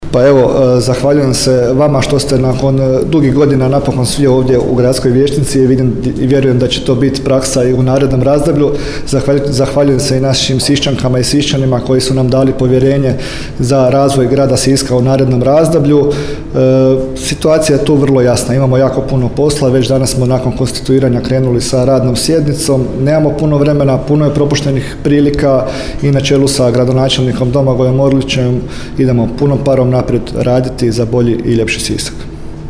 Tomislav Kralj, predsjednik Gradskog vijeća Grada Siska: